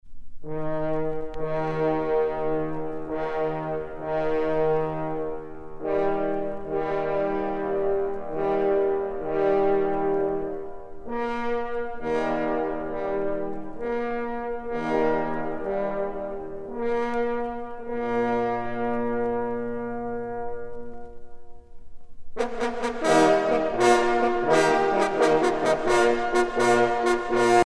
trompe - Fanfares et fantaisies de concert
circonstance : vénerie
Pièce musicale éditée